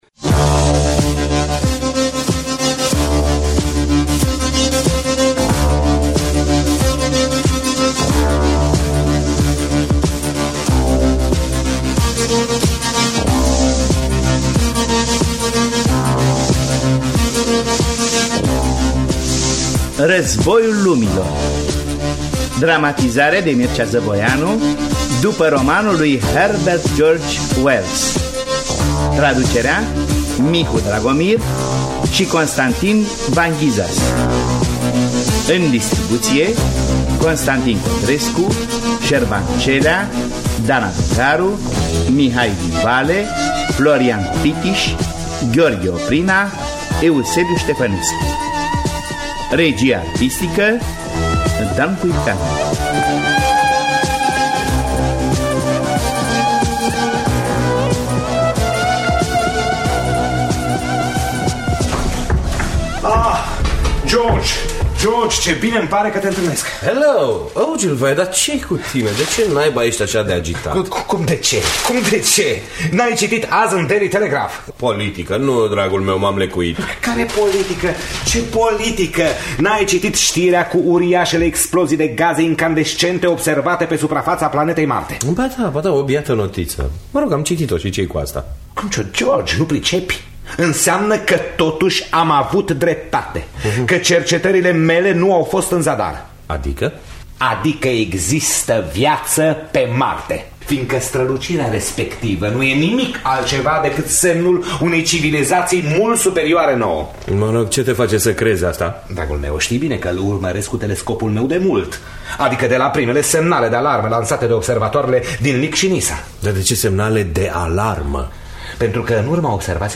Războiul lumilor de H.G. Wells – Teatru Radiofonic Online